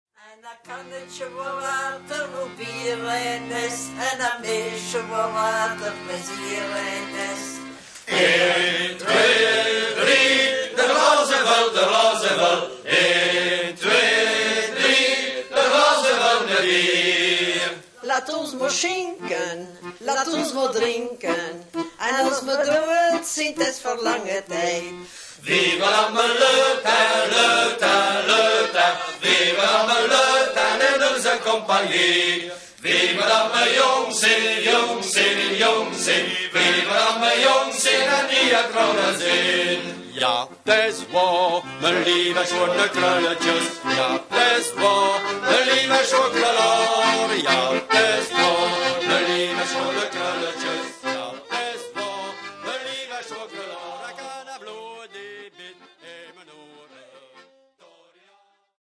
accordéons diatoniques
clarinette, chant
Chant, bouzouki, cornemuses
chant, guimbarde, percussions
face "live"